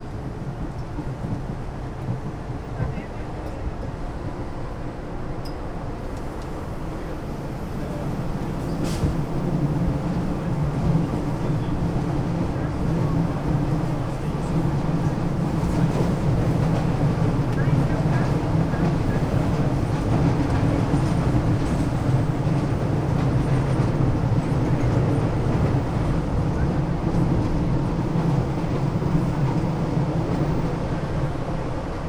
CSC-04-065-LE - Metro de brasilia dentro do vagao a ceu aberto com pessoas.wav